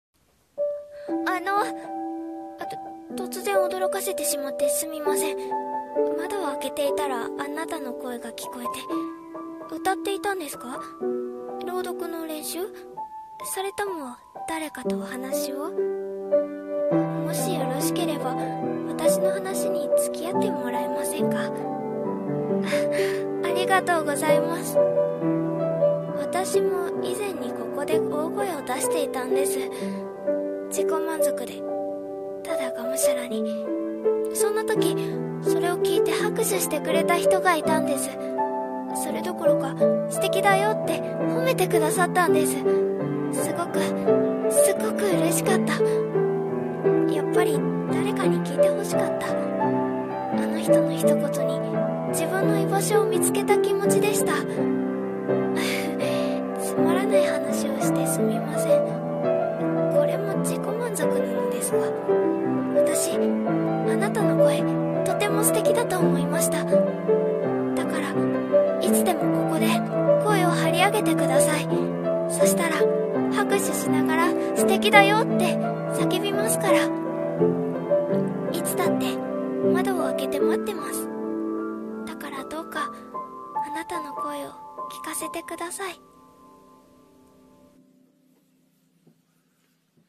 【一人声劇】あなたの声を